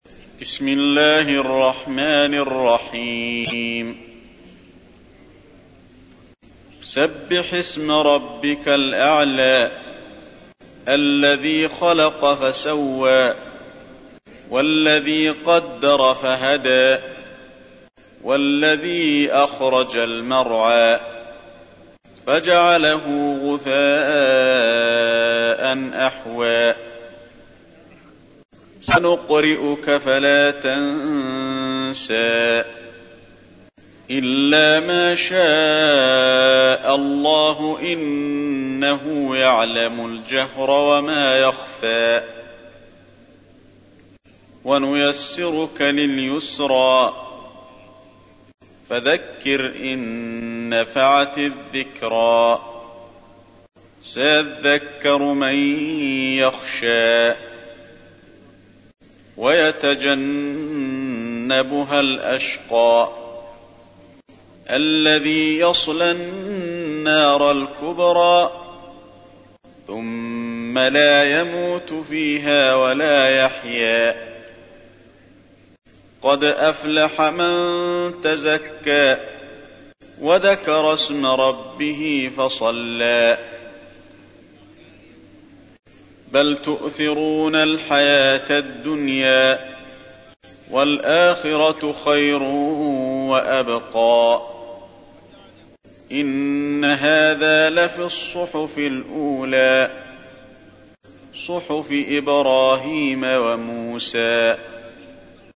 تحميل : 87. سورة الأعلى / القارئ علي الحذيفي / القرآن الكريم / موقع يا حسين